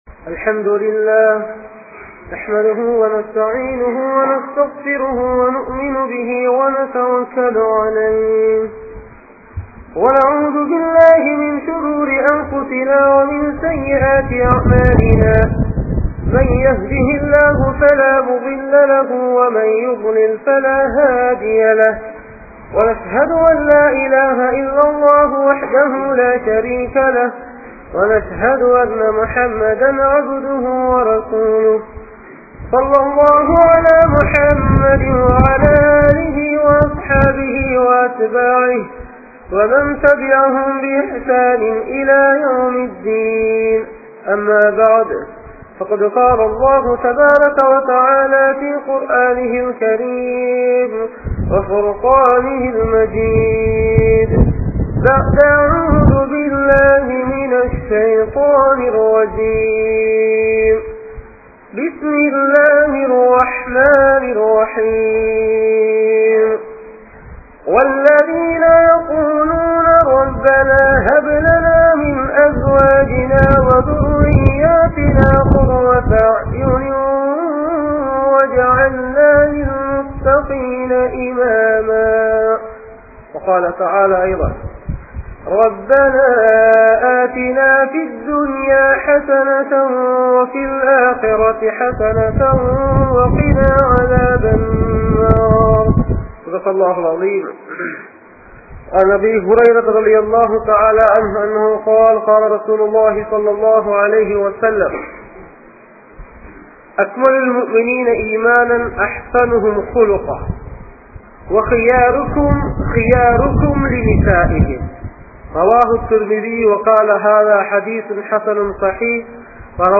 Santhoasamaana Kudumba Vaalkai (சந்தோசமான குடும்ப வாழ்க்கை) | Audio Bayans | All Ceylon Muslim Youth Community | Addalaichenai